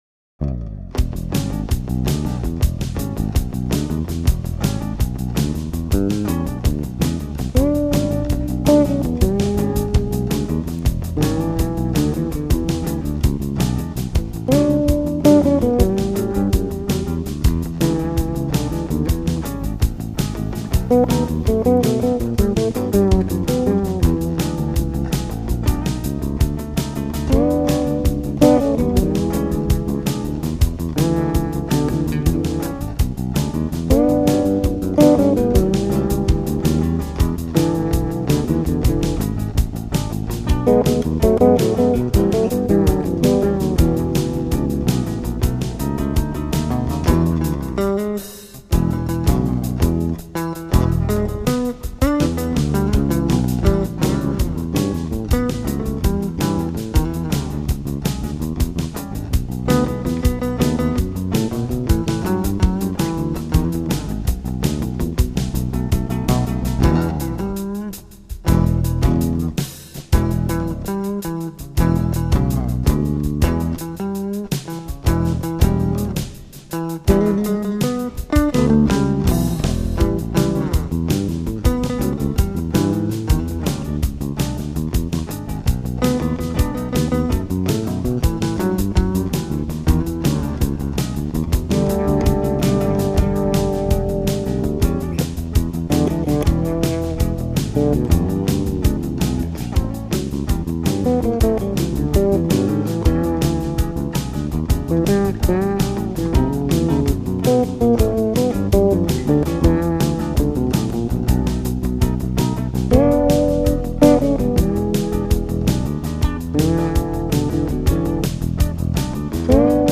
In a daichovo rhythm and with the lyrics somewhat twisted.